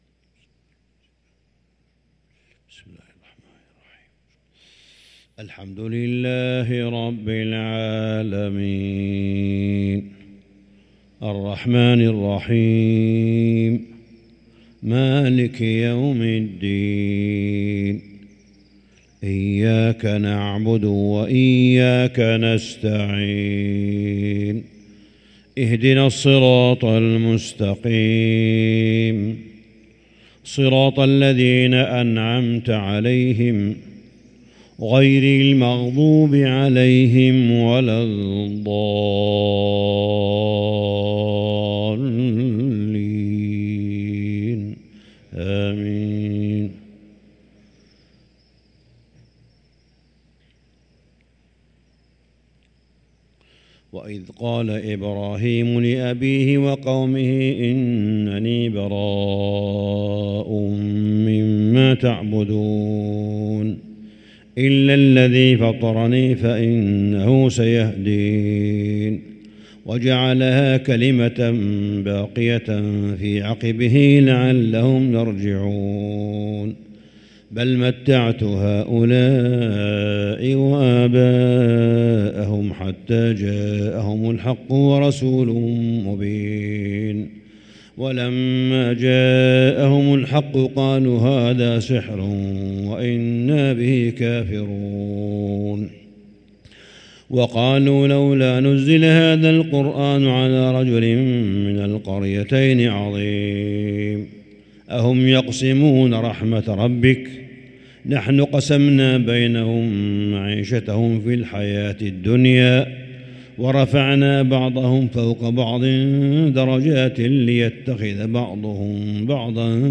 صلاة الفجر للقارئ صالح بن حميد 18 رمضان 1444 هـ